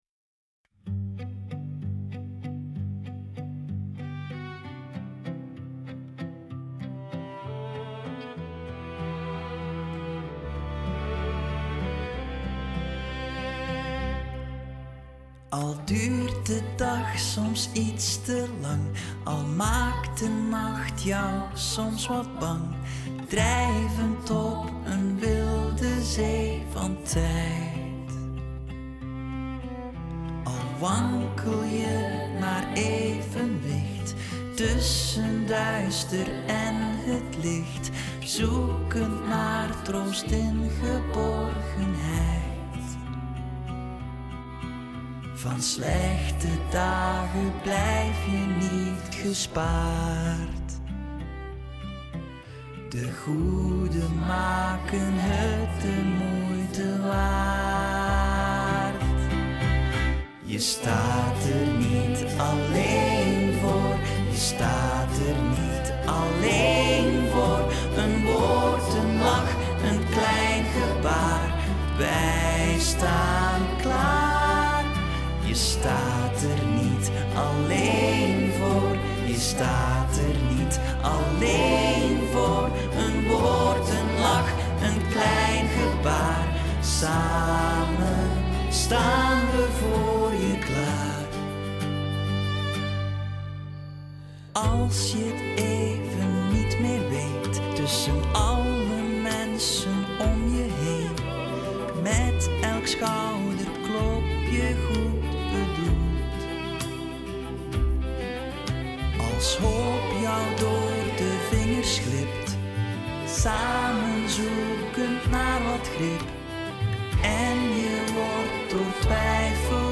een hoopvol lied voor mensen met kanker. Het – zelfgeschreven – lied bevat persoonlijke, warme en ontroerende boodschappen van patiënten, bezoekers en zorgverleners.